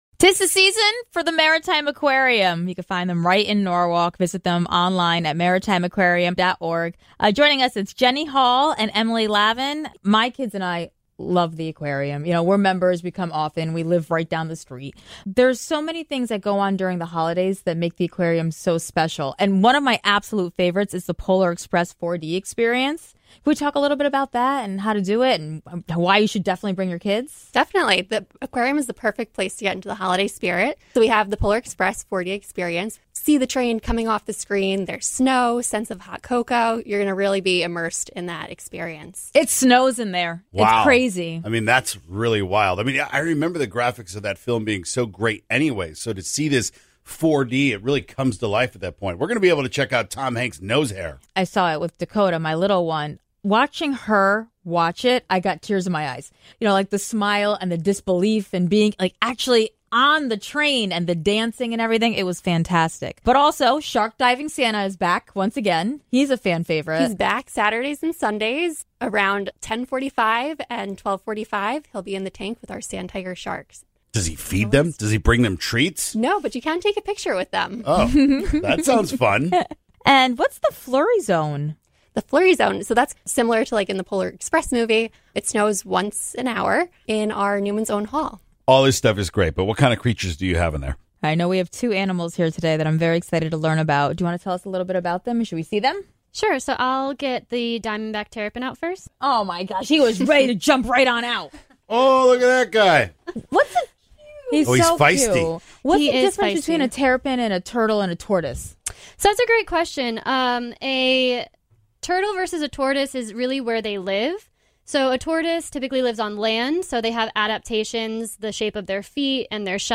Plus, they brought two special visitors with them to the studio..